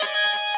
guitar_021.ogg